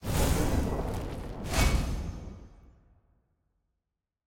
sfx_ui_combat_majordefeat.ogg